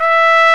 TRUMPET 2 E4.wav